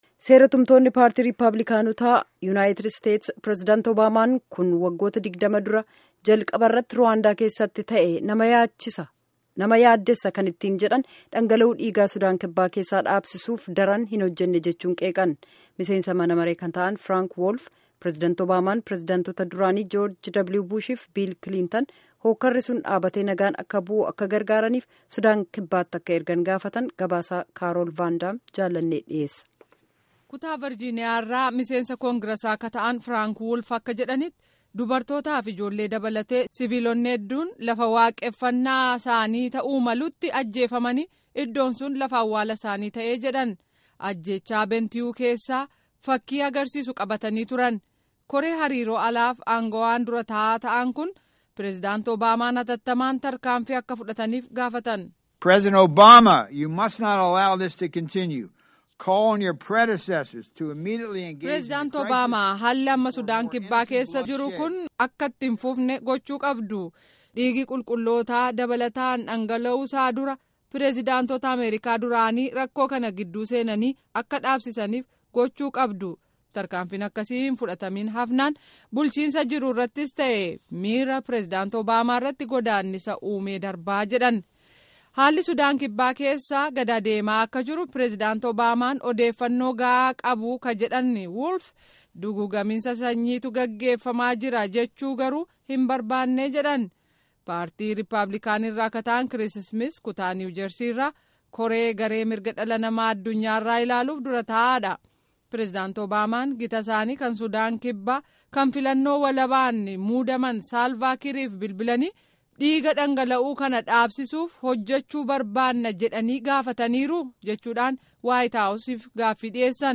Gabaasaa Guutuu Armaa Gaditti Caqasaa